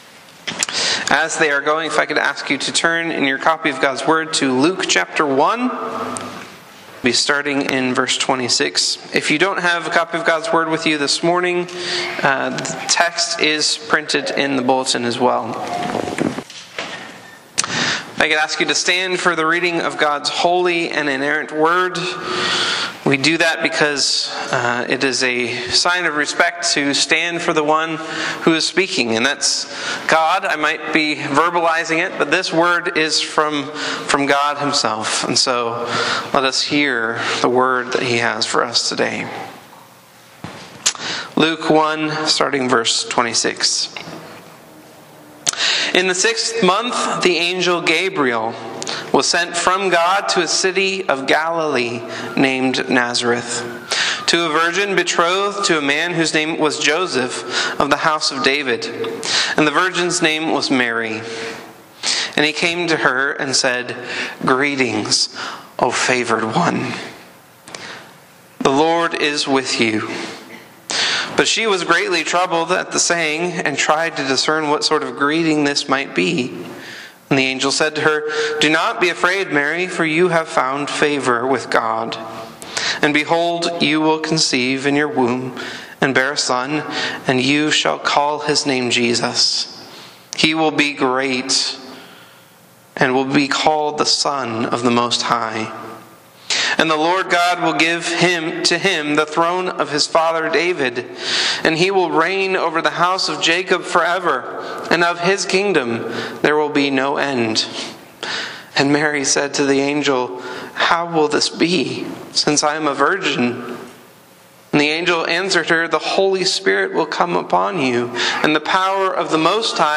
Sermons
Sermons from Gainesville Presbyterian Church: Gainesville, VA